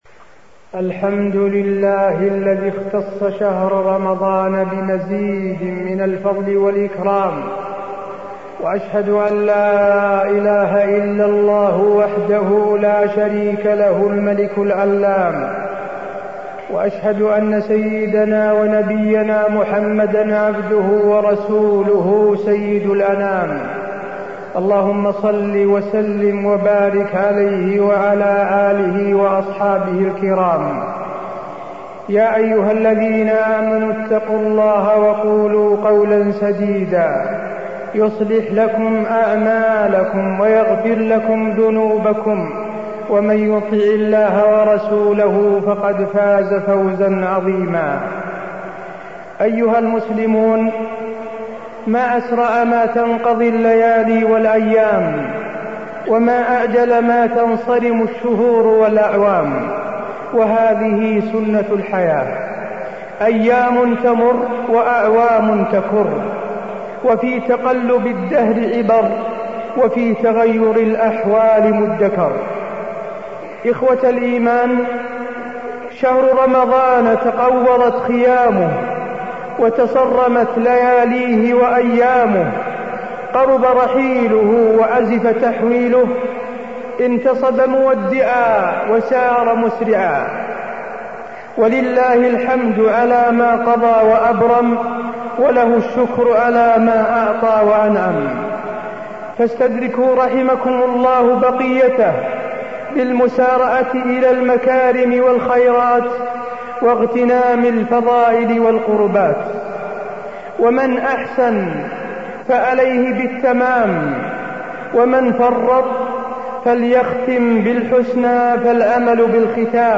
تاريخ النشر ١٥ رمضان ١٤٢٥ هـ المكان: المسجد النبوي الشيخ: فضيلة الشيخ د. حسين بن عبدالعزيز آل الشيخ فضيلة الشيخ د. حسين بن عبدالعزيز آل الشيخ فضائل الصيام